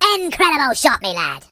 project_files/HedgewarsMobile/Audio/Sounds/voices/Pirate/Perfect.ogg